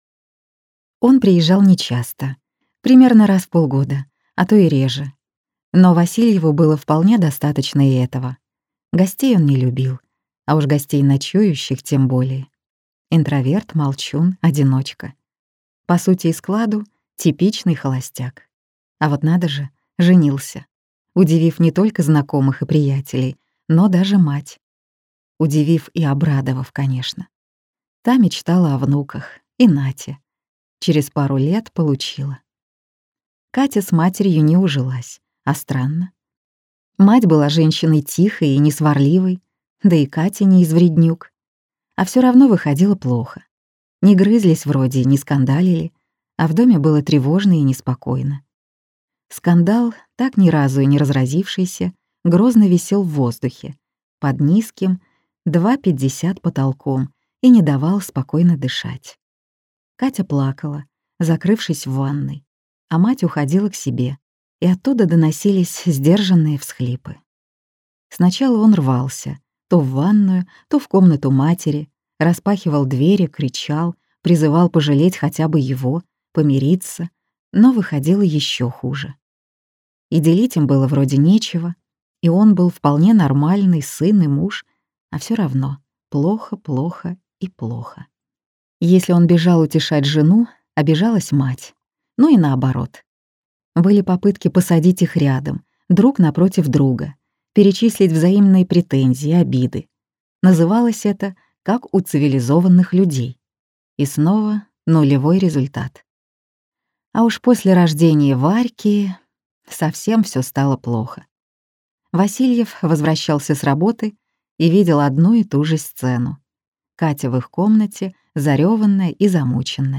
Аудиокнига Фотограф | Библиотека аудиокниг
Прослушать и бесплатно скачать фрагмент аудиокниги